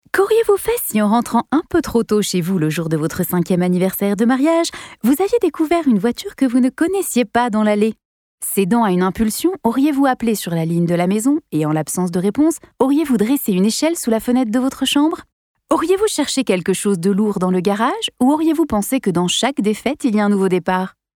Native Voice Samples
Commercial Demo
• Microphone: Neumann TLM 103
Mezzo-SopranoSopranoVery HighVery Low
AssuredDynamicEngagingMischievousSensualWarm